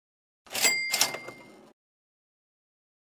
Cash Register Kaching
Cash-Register-Kaching-Sound-Effects-Download-.mp3